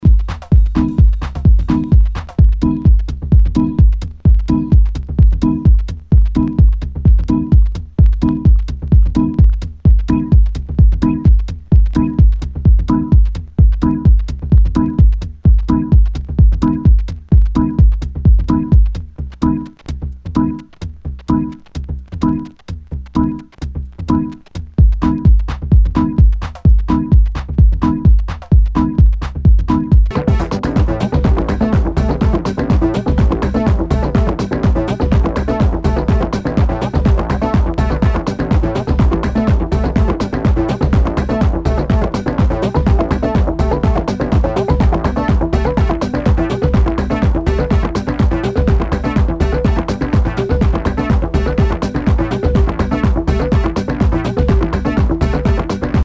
HOUSE/TECHNO/ELECTRO
ナイス！テック・ハウス / ミニマル！
全体にチリノイズが入ります